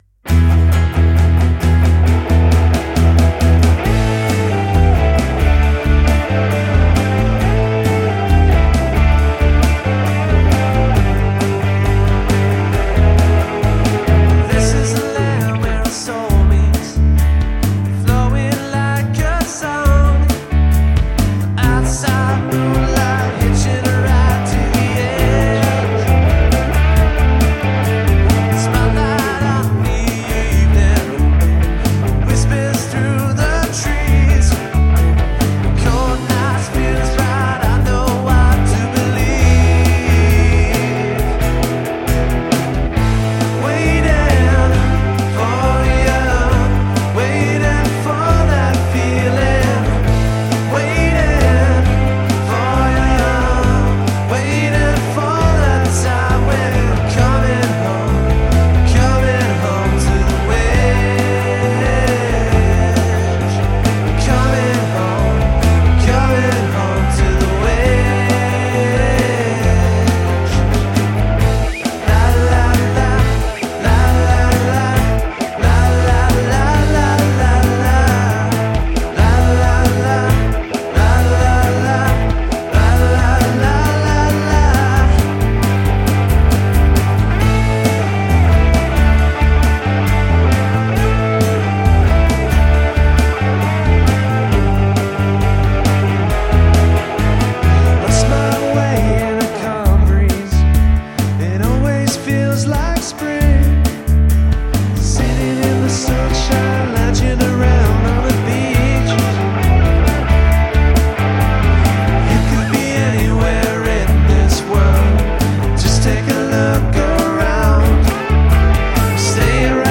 These tunes are hot off the home mic – no fancy studios, just pure DIY brilliance.